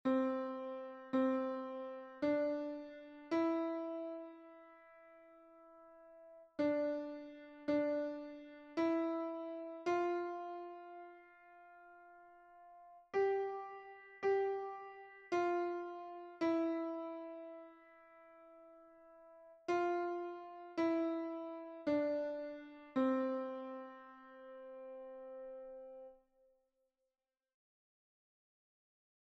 You have the sound file but with no metronome beat.